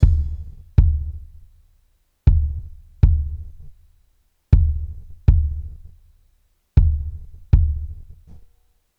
Index of /m8-backup/M8/Samples/musicradar-metal-drum-samples/drums acoustic/058bpm_drums_acoustic